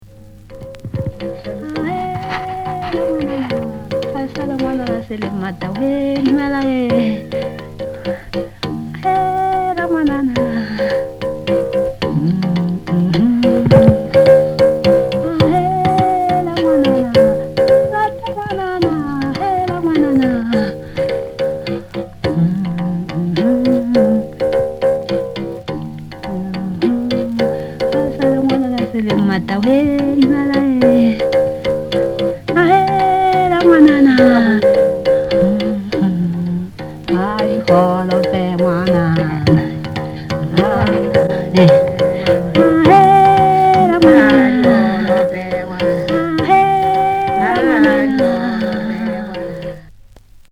アフリカ南部に位置する、英国植民地時代のバストランド(現在のレソト王国)で50年代に録音されたソト族の音楽。
仕事の合間に牧夫が爪弾く原始弓琴の唄うような軋み、口腔を変調器に見立てた弓口琴の滋味深い倍音など、音霊宿る録音の数々。
キーワード：現地録り